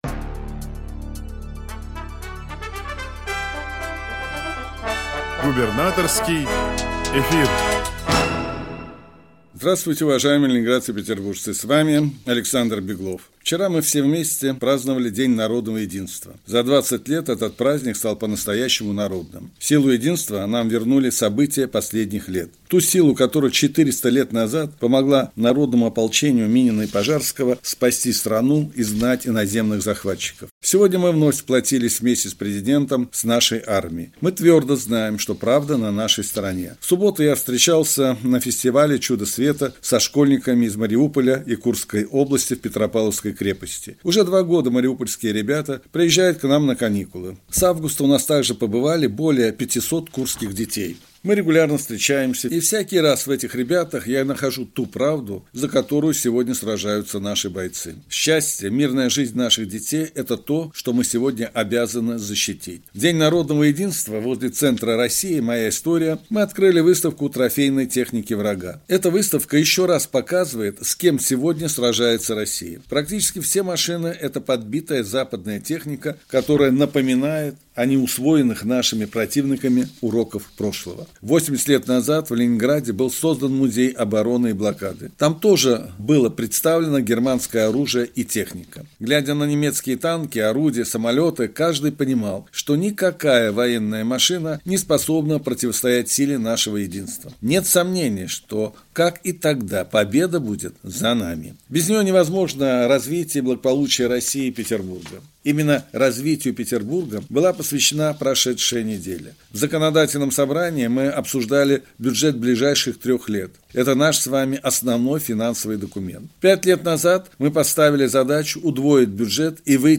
Радиообращение Губернатора Санкт‑Петербурга А.Д.Беглова от 05.11.2024